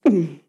Persona tragando: deglución
Sonidos: Acciones humanas